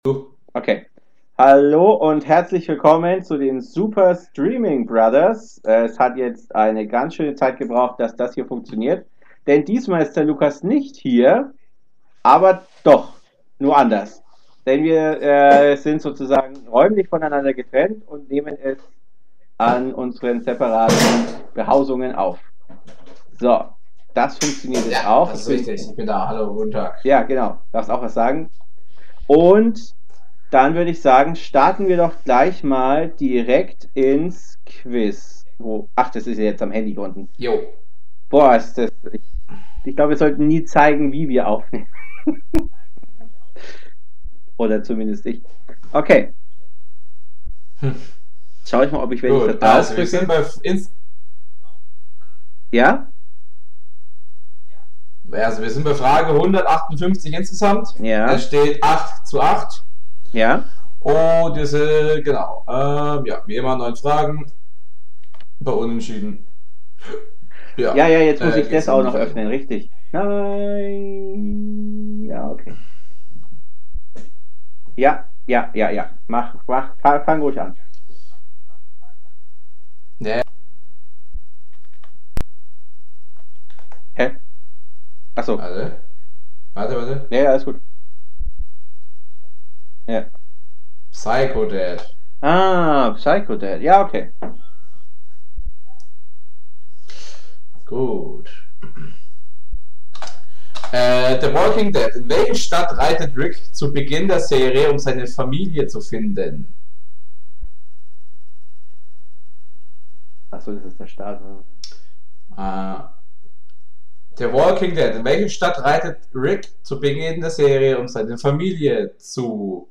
Sorry, leider ist die Quali nicht der Hit. Wir mussten getrennt aufnehmen, hat eine Stunde zum einrichten gedauert. Und dann schaltet sich noch das Micro um.